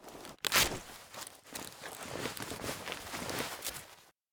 bandage.ogg